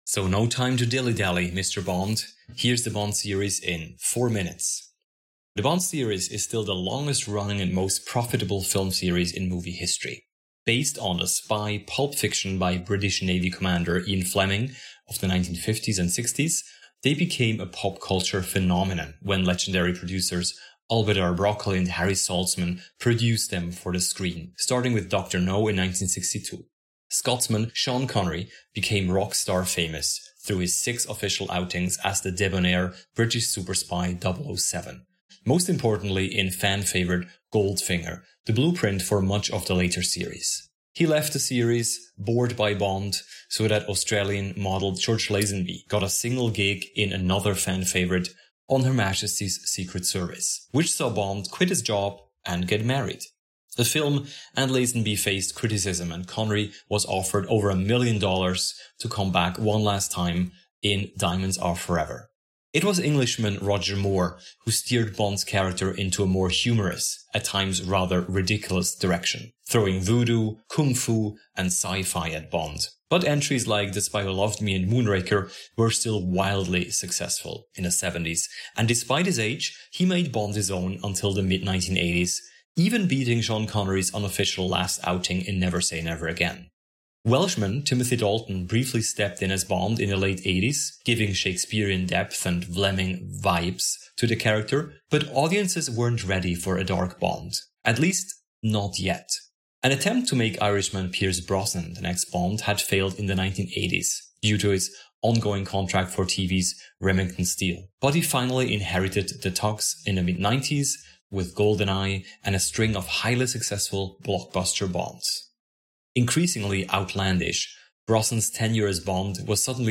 P.S.: Listen out for the Bond-appropriate pre-credit sequence!